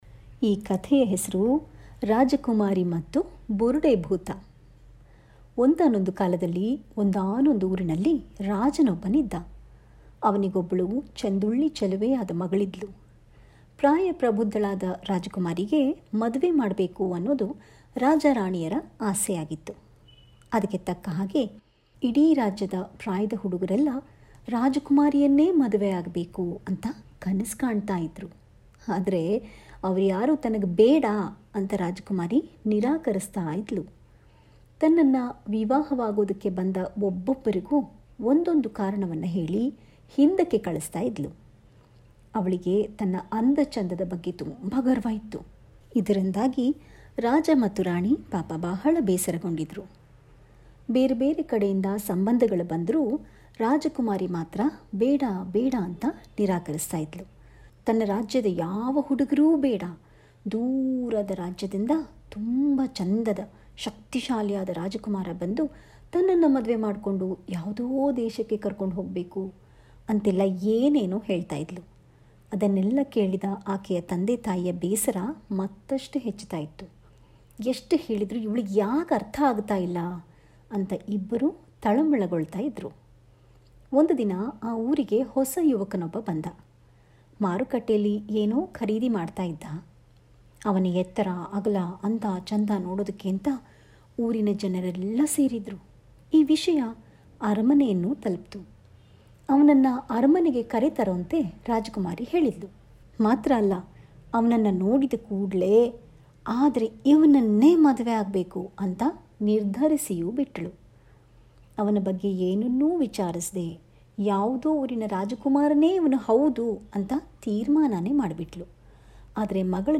ಮಕ್ಕಳ ಕಥೆ | ರಾಜಕುಮಾರಿ ಮತ್ತು ಬುರುಡೆ ಭೂತ